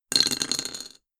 shaizi.mp3